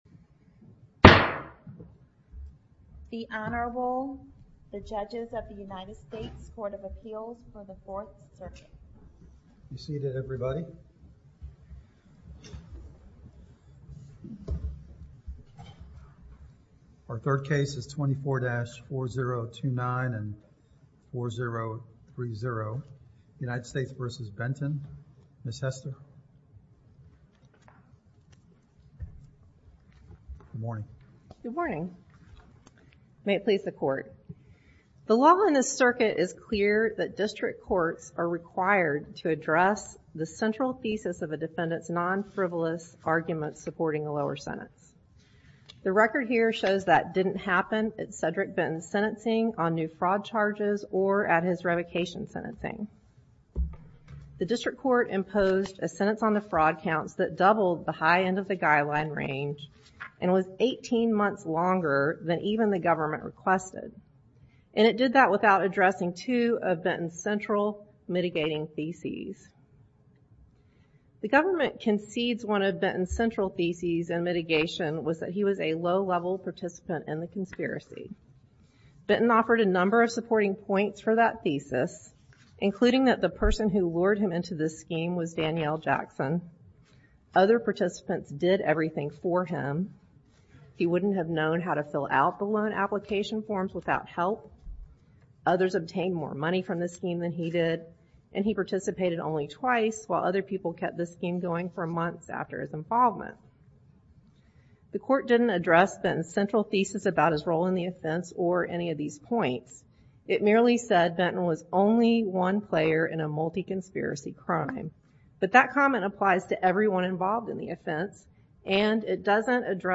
A chronological podcast of oral arguments with improved files and meta data.
Oral Arguments for the Court of Appeals for the Fourth Circuit